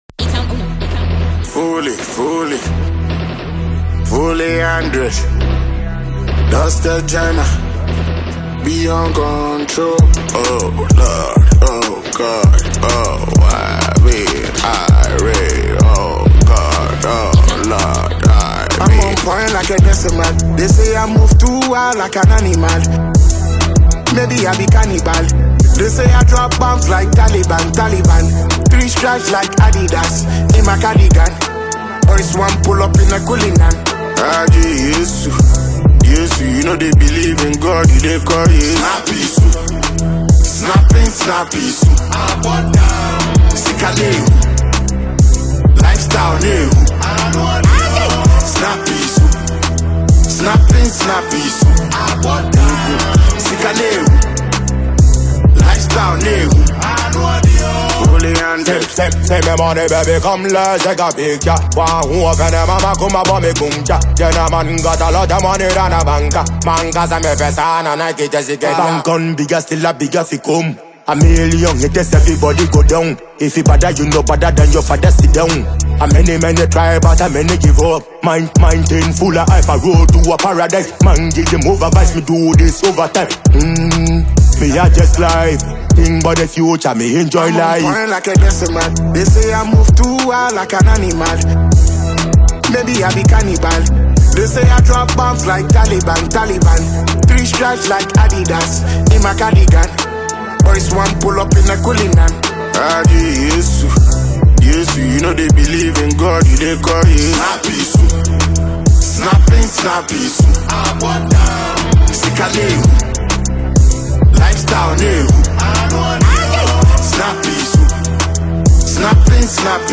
Afro-Fusion
its energetic delivery, catchy structure